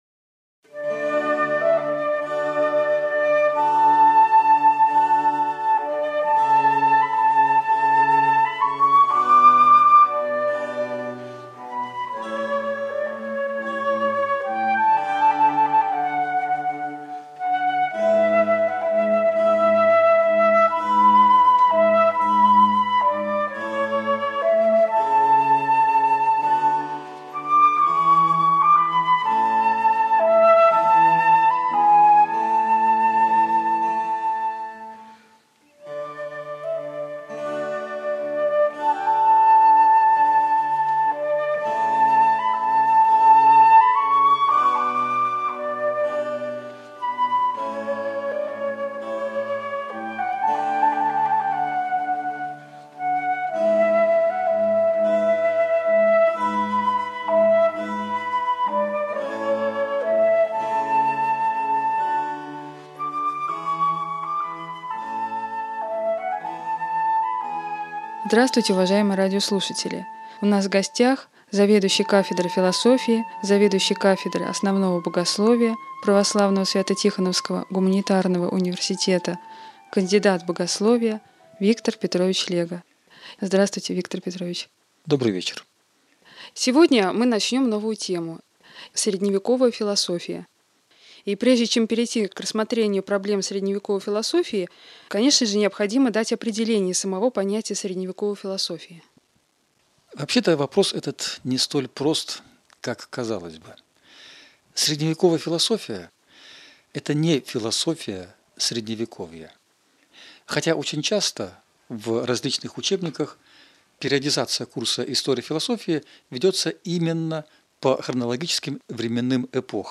Цикл бесед на тему «История философии».